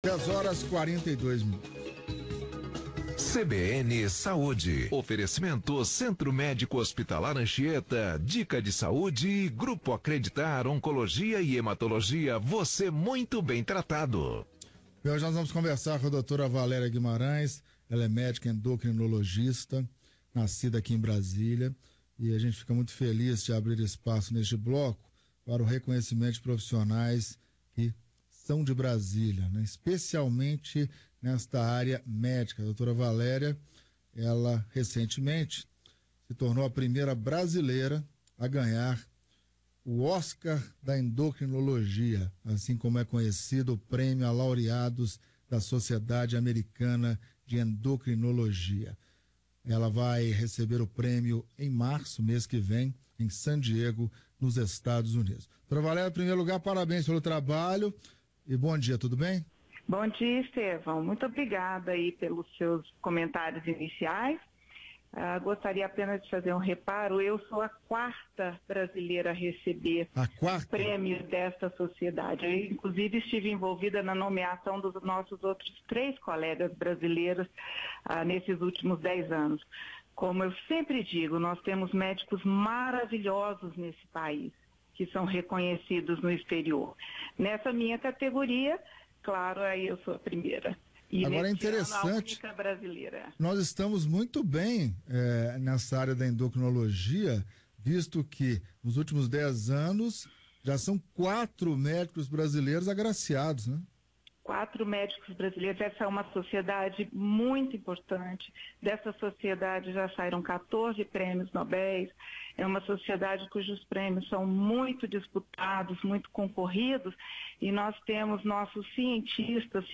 Entrevista para a Rádio CBN em 09/02/2015